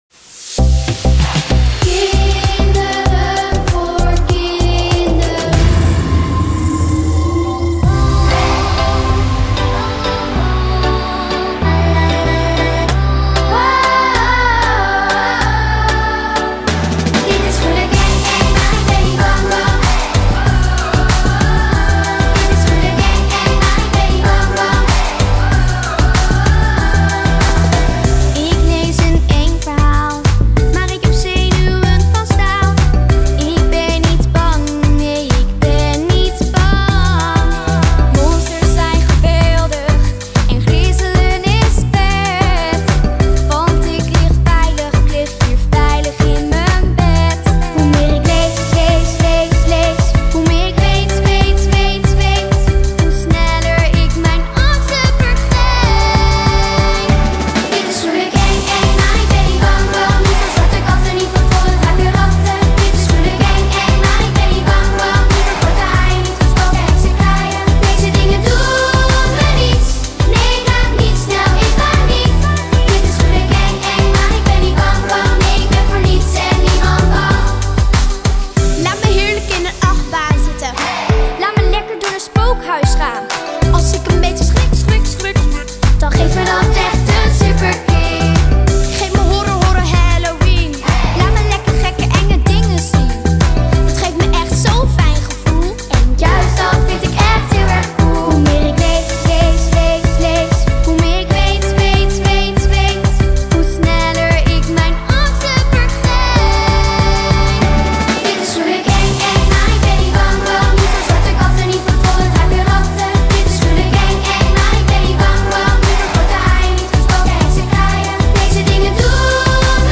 liedje